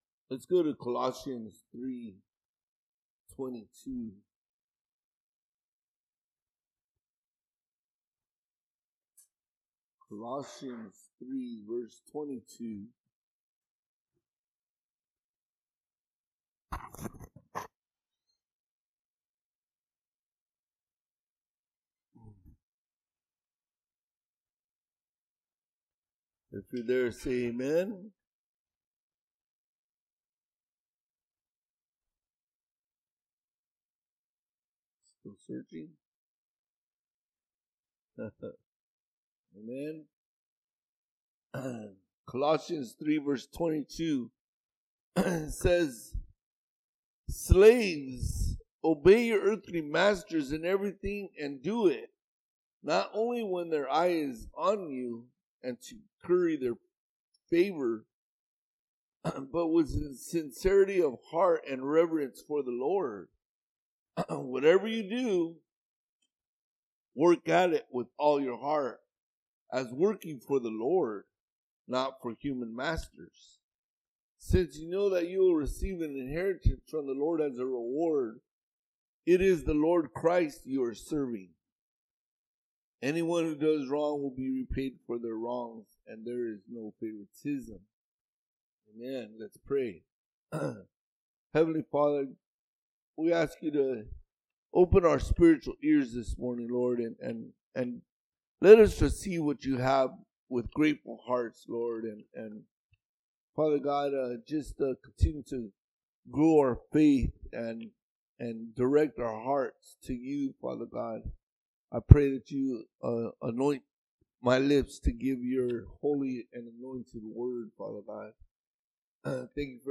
All Sermons Bondservant August 4